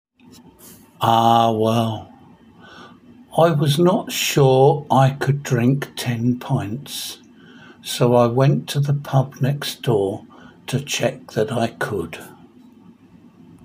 Each line is written in English and then in Spanish and has a recording of me reading it.